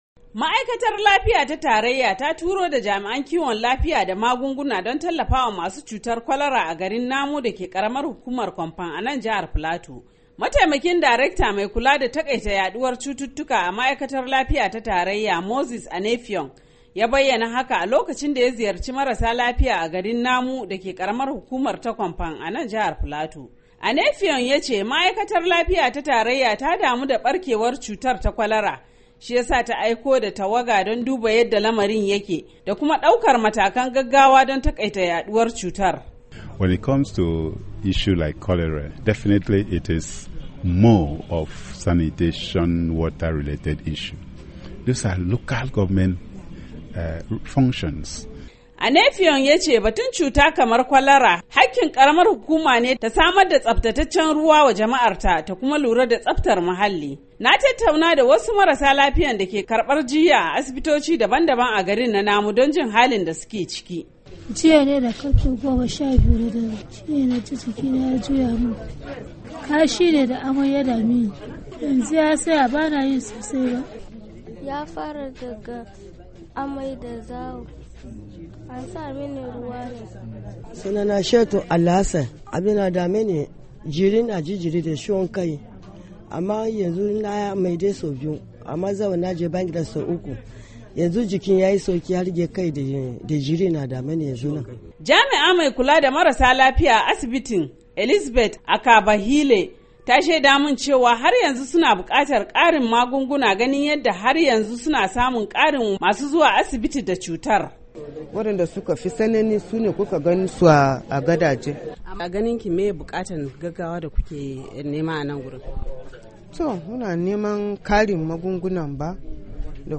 Rahoton barkewar kwalara a jihar Plato - 3:28